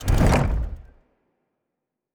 Special Click 19.wav